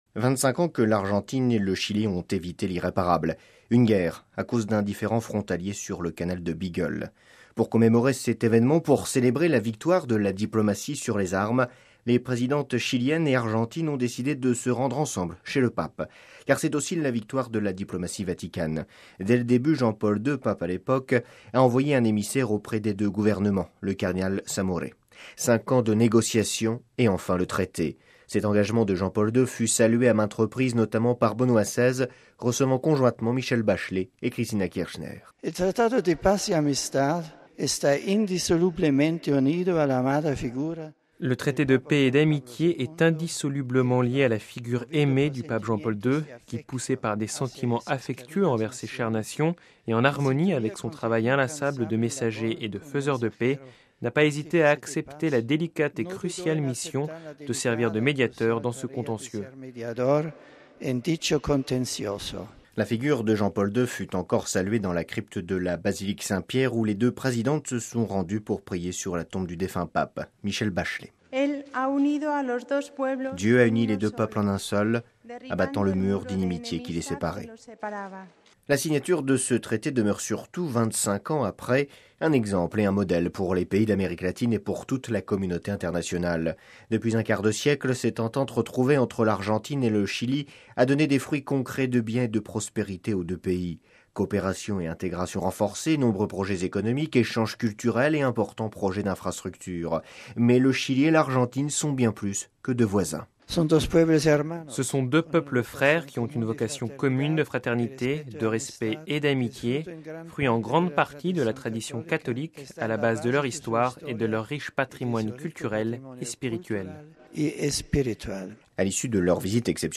Le compte rendu